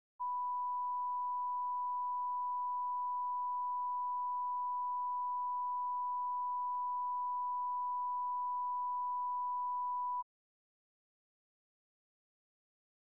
Conversation: 369-007
Recording Device: Old Executive Office Building
On October 18, 1972, President Richard M. Nixon and H. R. ("Bob") Haldeman met in the President's office in the Old Executive Office Building at an unknown time between 7:55 am and 8:20 am. The Old Executive Office Building taping system captured this recording, which is known as Conversation 369-007 of the White House Tapes.